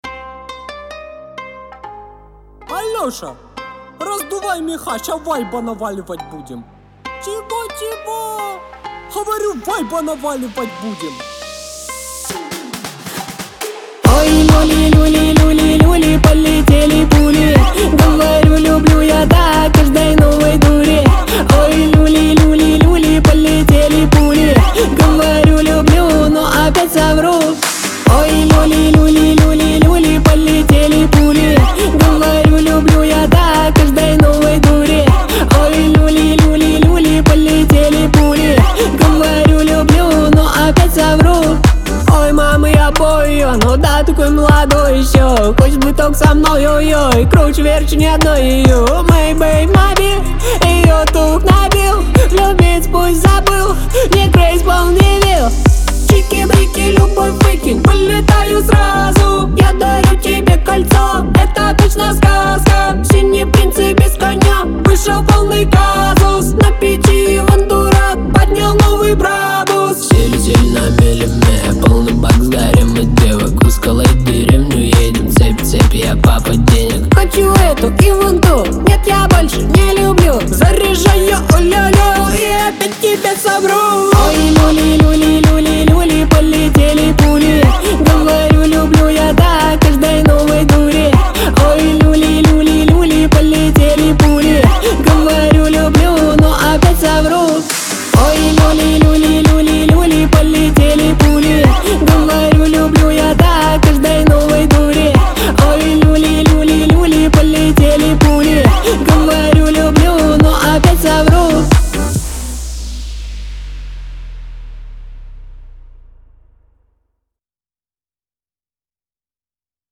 инди-поп